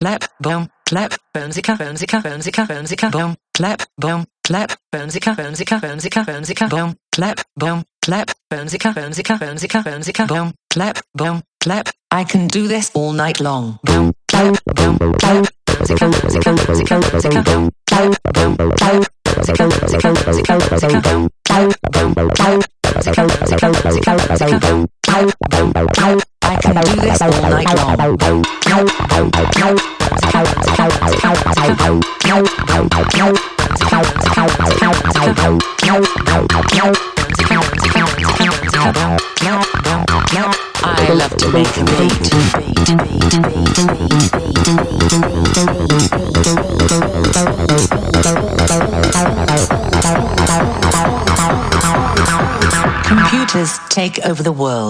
• Качество: 320, Stereo
Electronic
электронный голос
клубная музыка
Техно